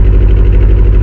Buggy_Low.wav